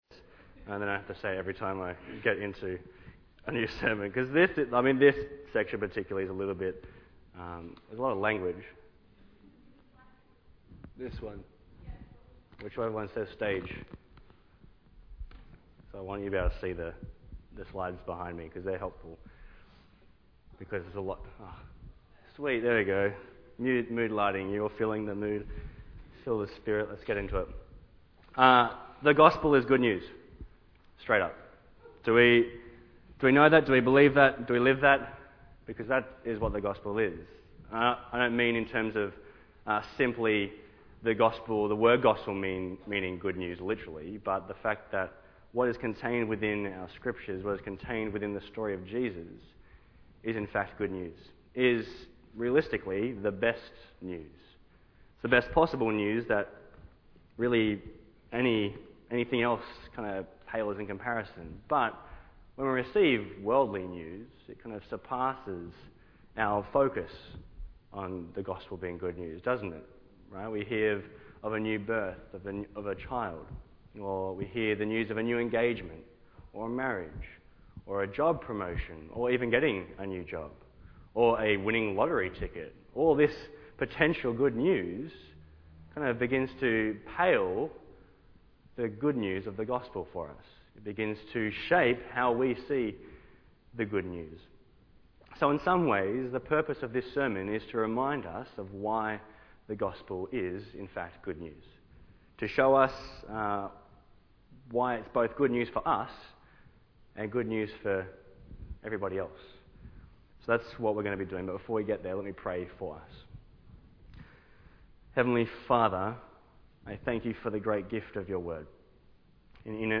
The Promised Inheritance – Macquarie Chapel